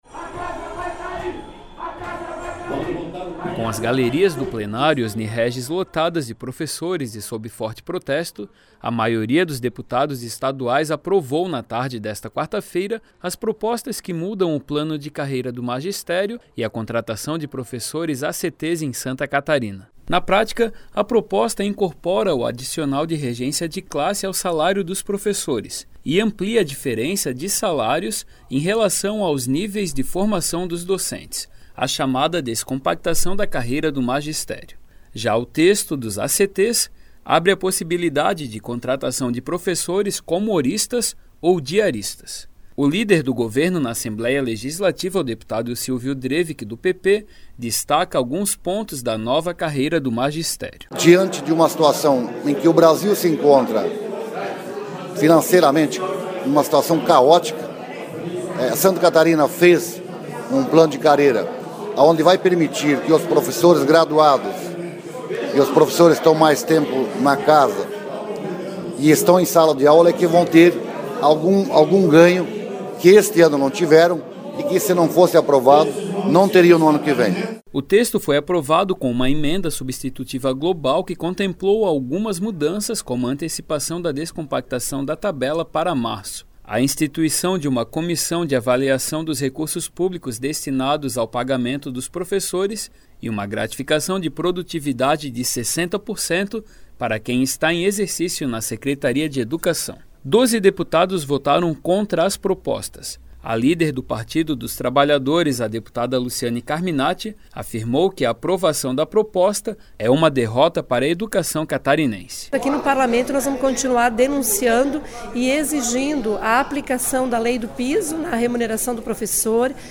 Entrevistas com